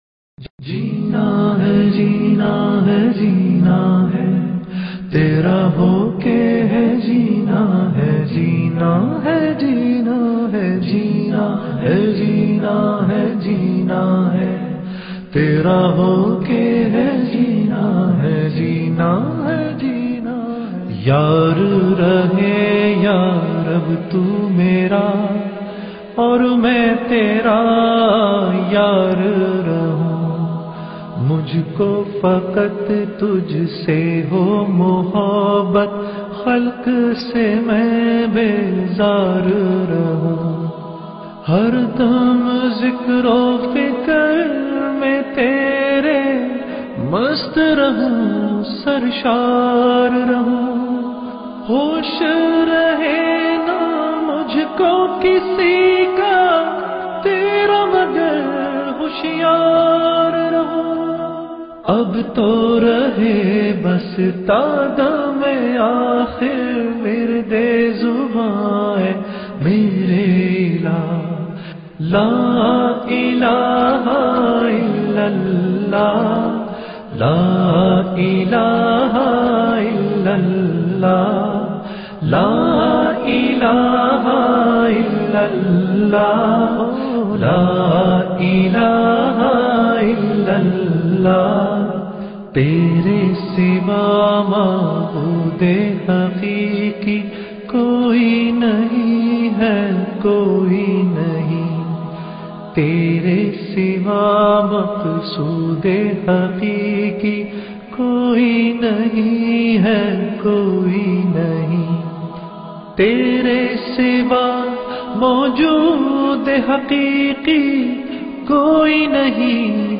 in best audio quality
1964 was a Pakistani musician